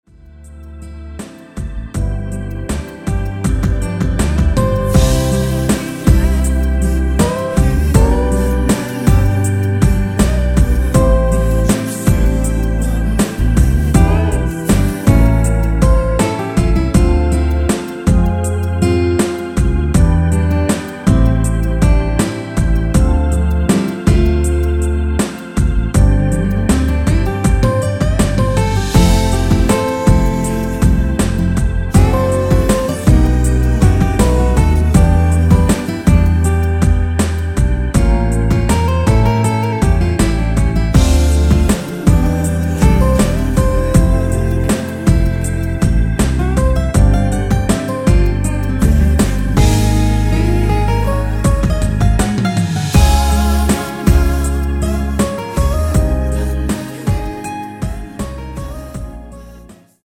원키 코러스 포함된 MR 입니다.(미리듣기 참조)
앞부분30초, 뒷부분30초씩 편집해서 올려 드리고 있습니다.
중간에 음이 끈어지고 다시 나오는 이유는